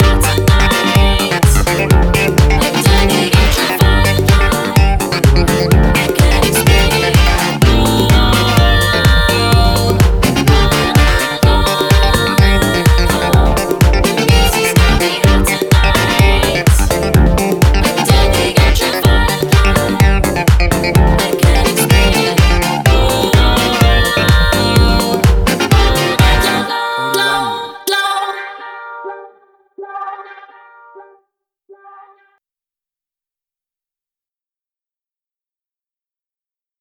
Tempo (BPM): 126